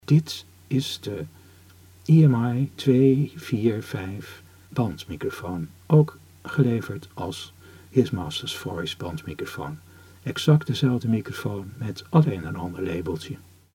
Deze microfoons hebben hun weg gevonden naar opnamestudio's en hoewel hun geluid vaak vrij donker is, zelfs voor een ribbonmicrofoon, leveren ze met een vers bandje en een moderne transformator een goede geluidskwaliteit.